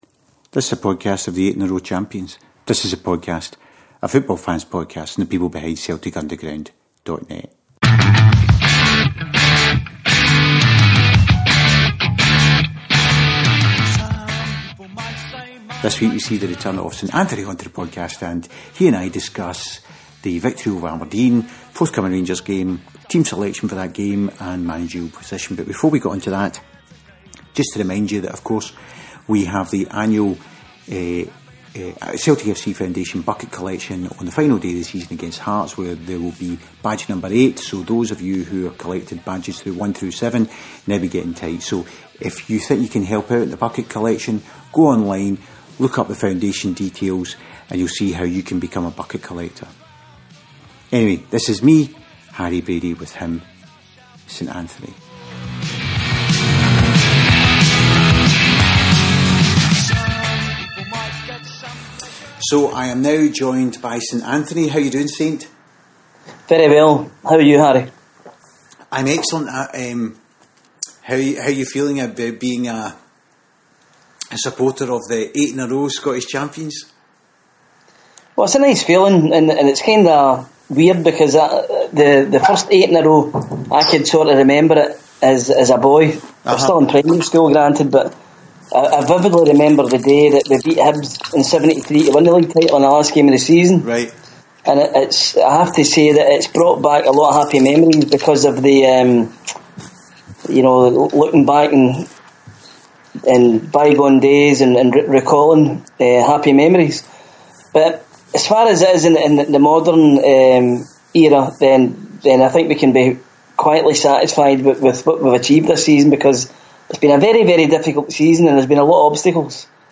This is a football fans podcast of the 8, yes EIGHT in a row Scottish champions who are now just 90 minutes from a treble.